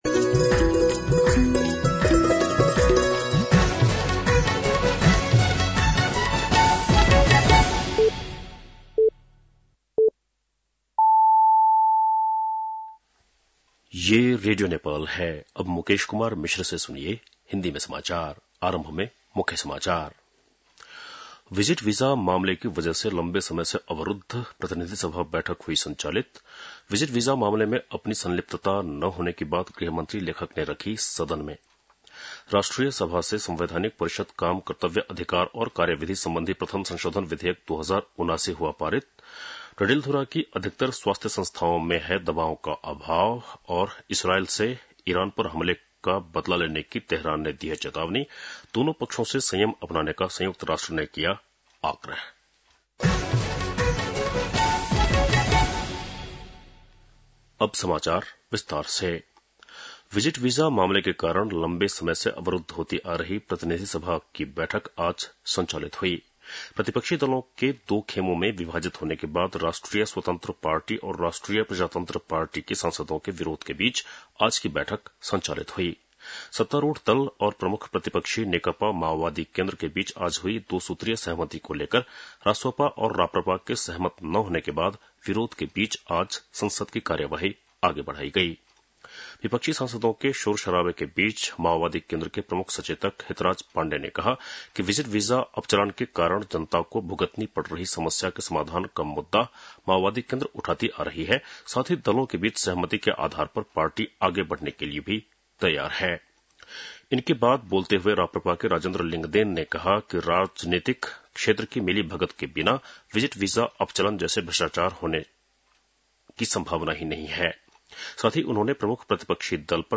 बेलुकी १० बजेको हिन्दी समाचार : ३० जेठ , २०८२